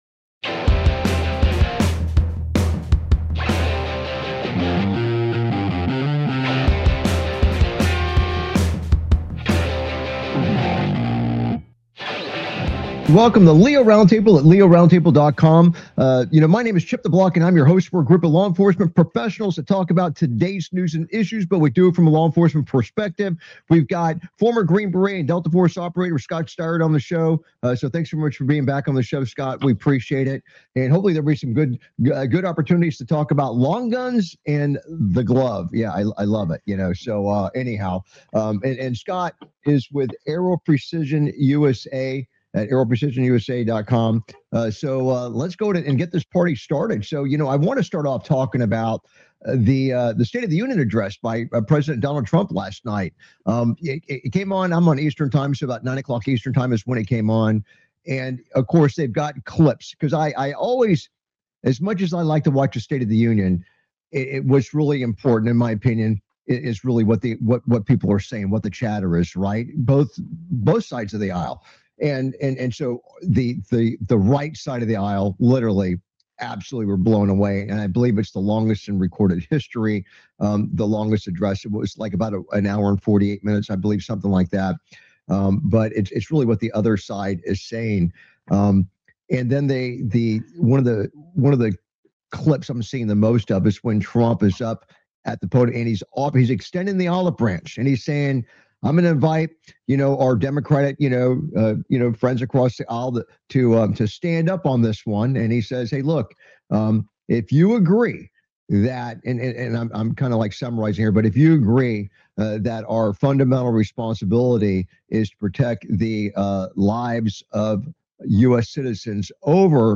Talk Show Episode, Audio Podcast, LEO Round Table and S11E040, Narcotics Search Warrant Turns Ugly When Bad Guy Shows Up Pointing Rifle! on , show guests , about Trump delivers State of The Union Address,Declassified CIA files reveal plan for mind control through vaccines,Details released of death of Mexican Cartel leader,Multiple inmates escape after prison break in Mexico,Narcotics search warrant turns ugly when bad guy shows up pointing rifle,Welfare check ends in the fatal shooting of man armed with a knife,Tactical Analysis of National Security and Law Enforcement Incidents,Tactical & Political Briefing,Analysis of the 2026 SOTU,CIA Project Artichoke,Mexican Cartel Warfare, categorized as Entertainment,Military,News,Politics & Government,National,World,Society and Culture,Technology,T